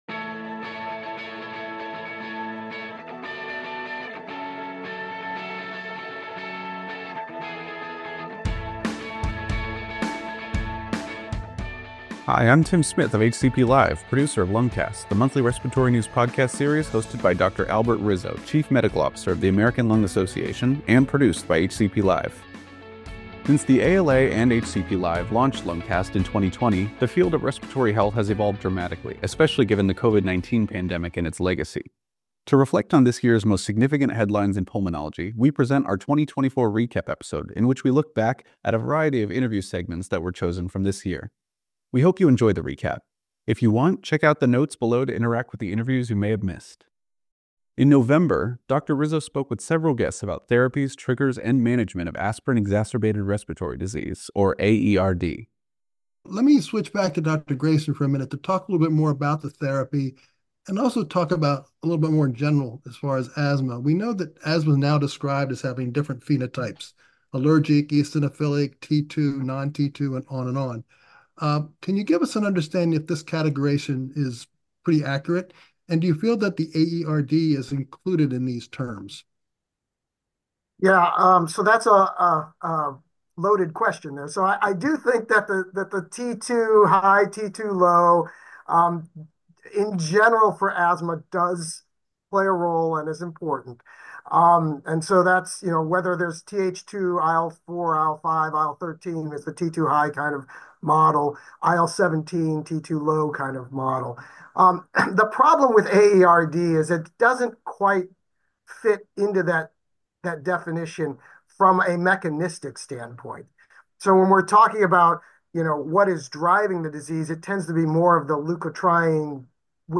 In this recap episode, the Lungcast team reflects on these conversations by highlighting some of the many interviews featured in 2024.